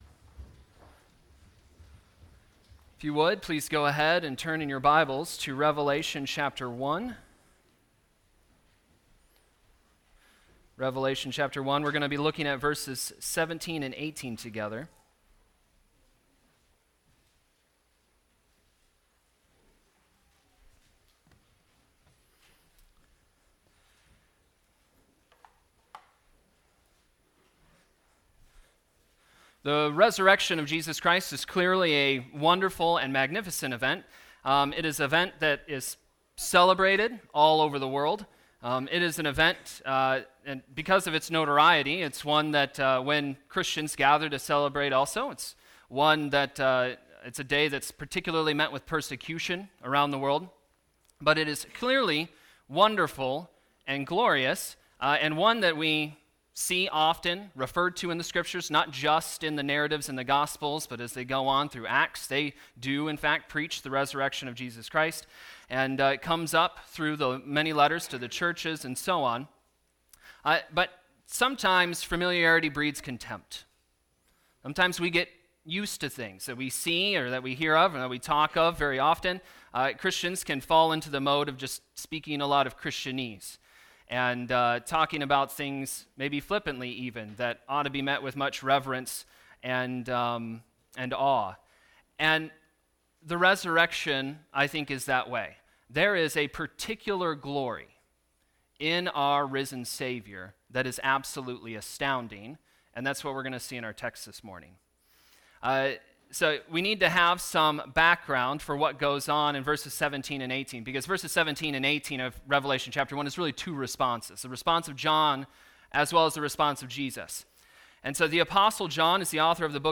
Resurrection Sunday Sermon